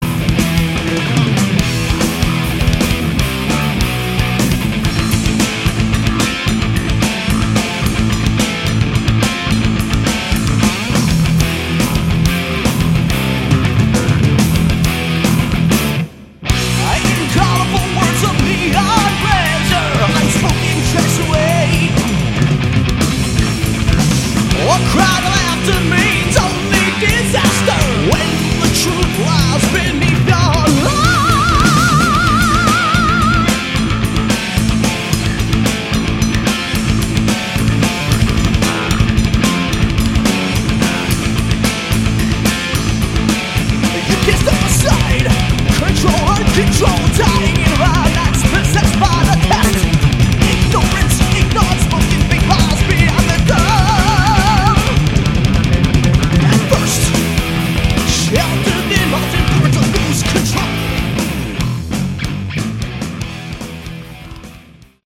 Category: Melodic Prog Metal
vocals
guitars
bass
drums